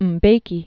(əm-bākē), Thabo Mvuyelwa Born 1942.